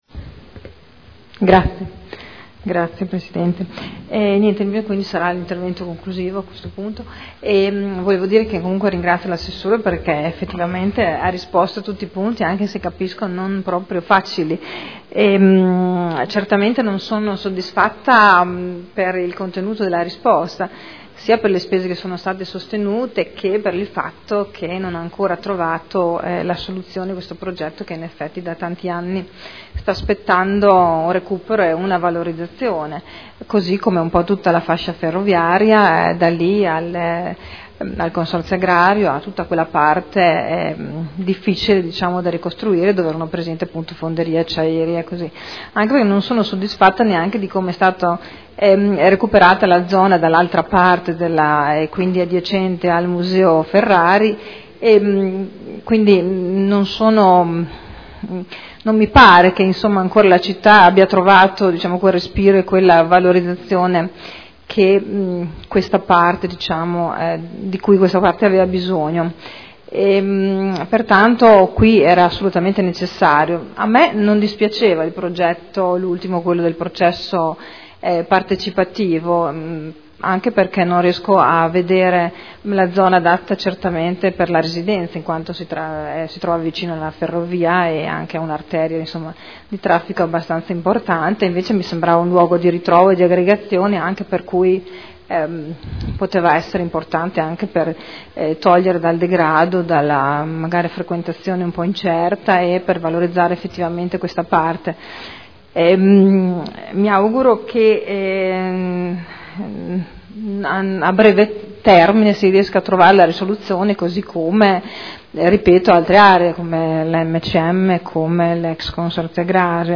Seduta del 28/01/2012 Dibattito. Interrogazioni dei Consiglieri Bellei e Poppi su Ex Fonderie.